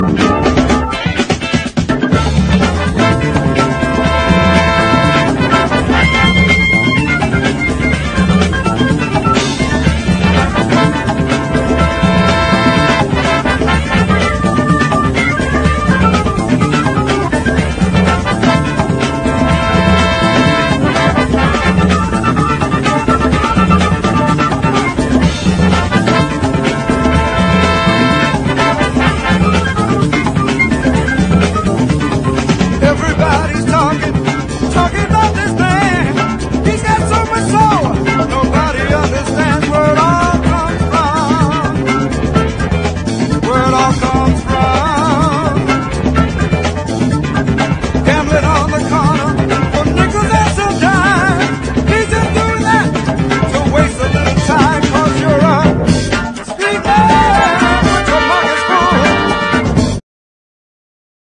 ROCK / 70'S / NEW ORLEANS / DRUM BREAK